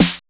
909 SPLATSN.wav